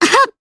Gremory-Vox_Attack4_jp.wav